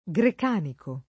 grecanico [ g rek # niko ]